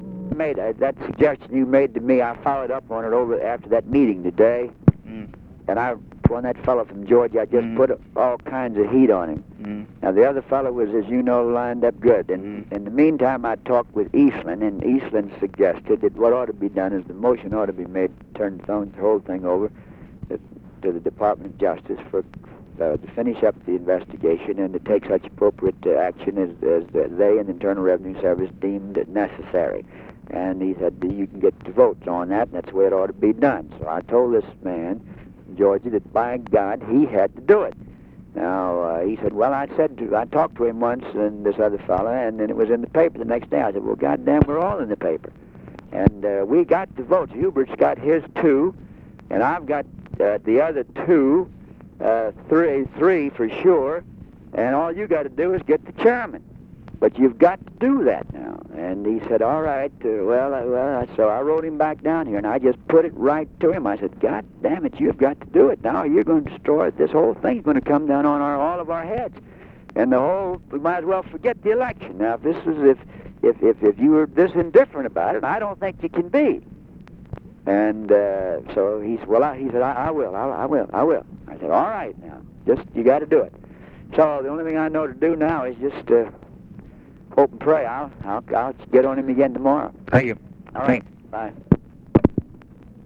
Conversation with GEORGE SMATHERS, January 29, 1964
Secret White House Tapes